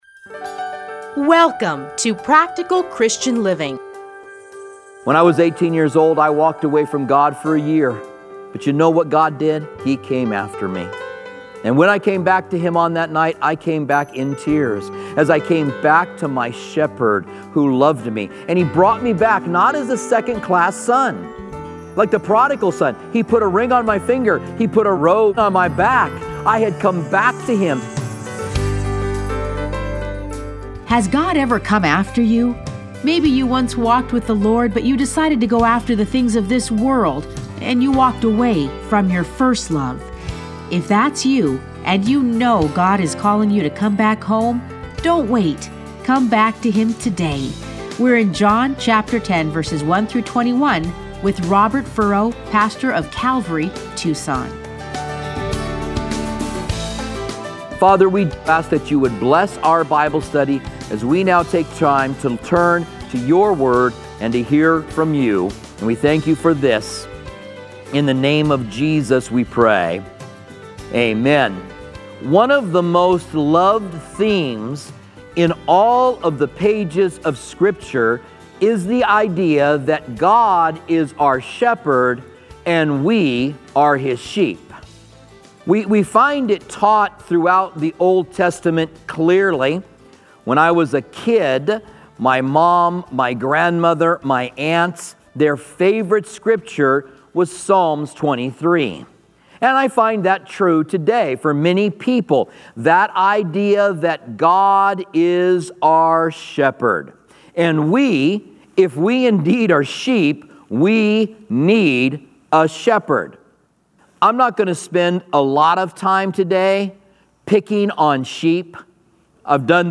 Listen to a teaching from John 9:13-41.